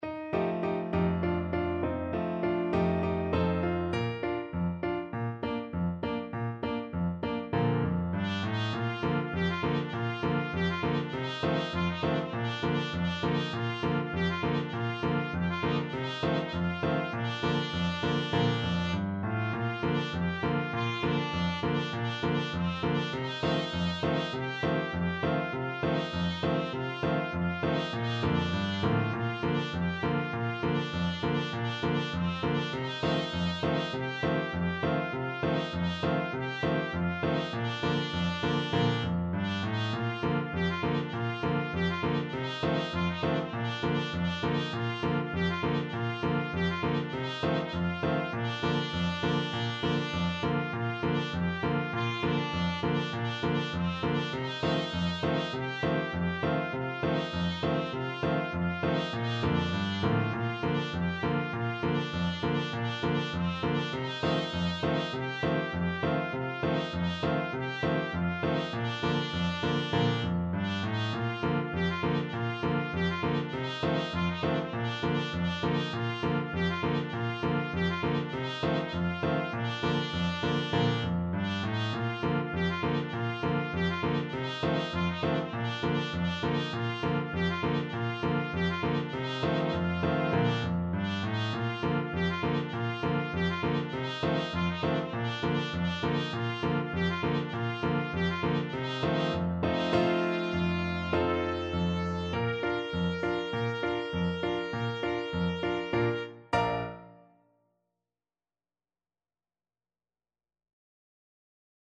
Traditional Music of unknown author.
2/4 (View more 2/4 Music)
~ = 100 Allegro moderato (View more music marked Allegro)
C5-Bb5
world (View more world Trumpet Music)